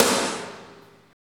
Index of /90_sSampleCDs/Roland L-CDX-01/KIT_Drum Kits 1/KIT_R&R Kit 2